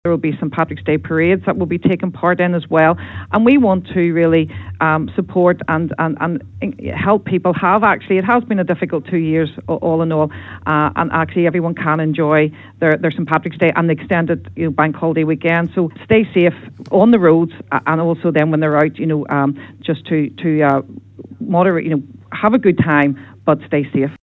Paula Hillman is Assistant Garda Commissioner for roads policing. She says there will be a large Garda presence across the country over the coming days, with the emphasis being placed on ensuring people have a safe and enjoyable break…………..